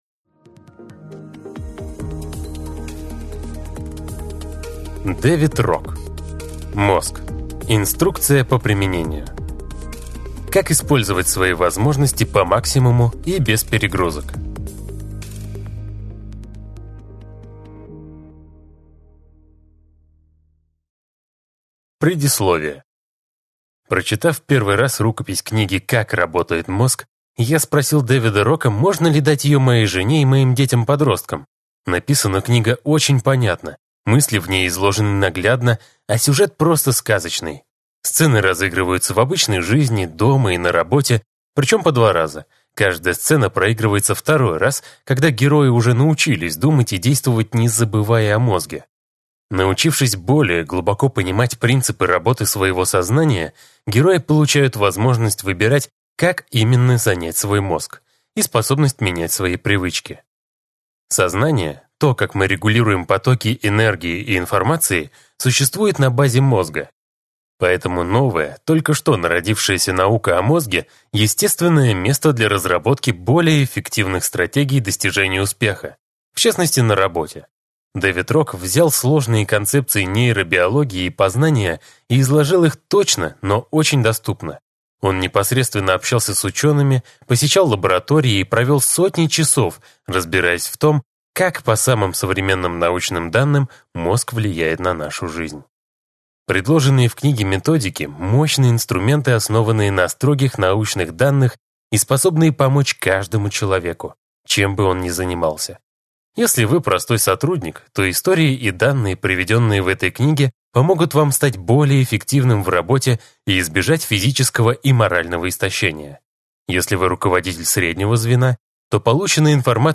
Аудиокнига Мозг. Инструкция по применению. Как использовать свои возможности по максимуму и без перегрузок | Библиотека аудиокниг